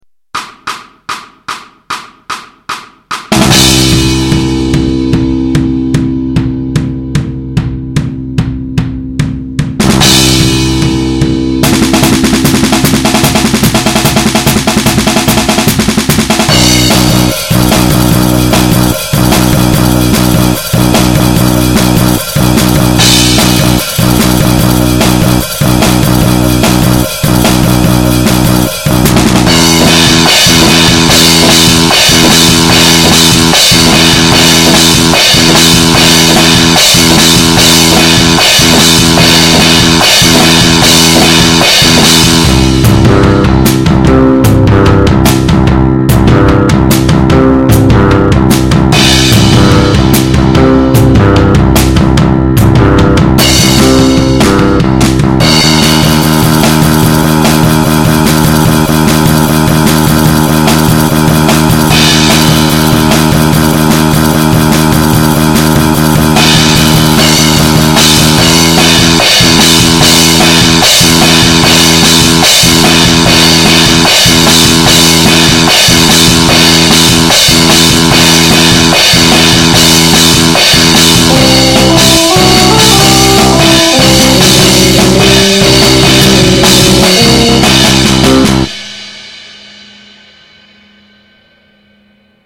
Playbacks instrumental